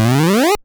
その他の効果音
溜めるＡ３段